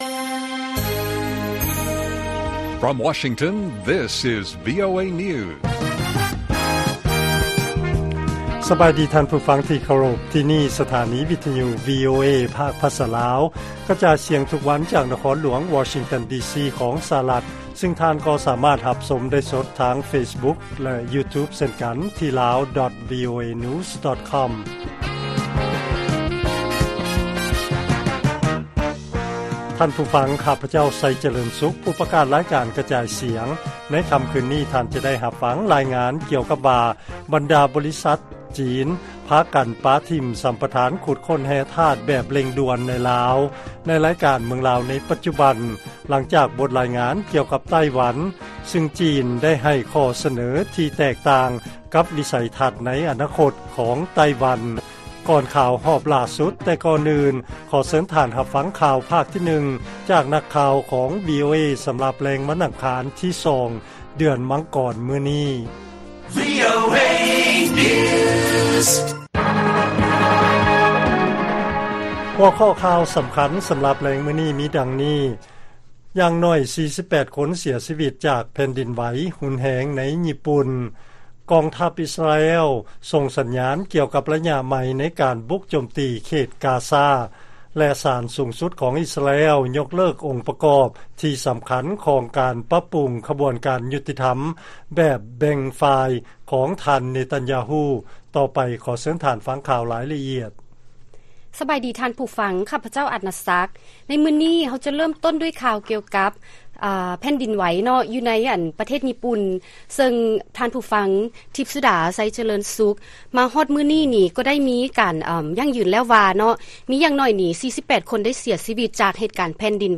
ລາຍການກະຈາຍສຽງຂອງວີໂອເອ ລາວ: ມີຢ່າງໜ້ອຍ 48 ຄົນເສຍຊີວິດ ຈາກແຜ່ນດິນໄຫວຮຸນແຮງໃນ ຍີ່ປຸ່ນ